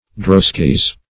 (dr[o^]s"k[i^]z).